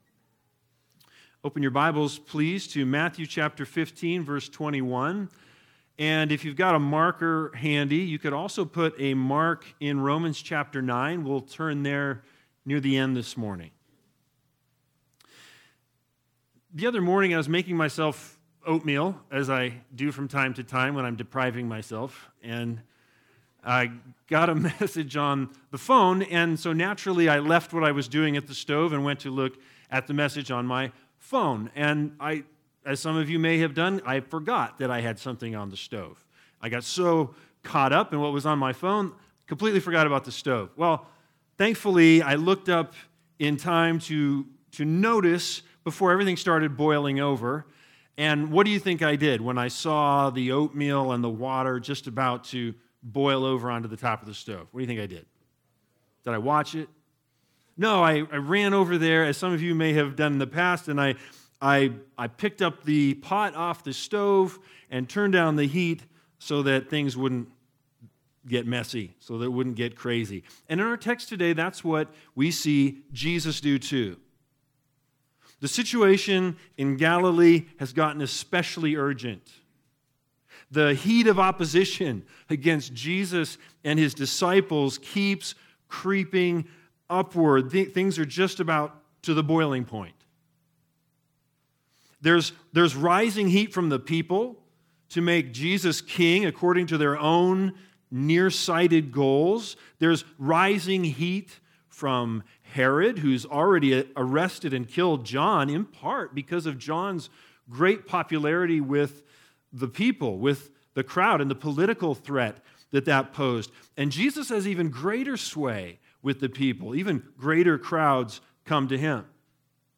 Matthew 15:21-28 Service Type: Sunday Sermons BIG IDEA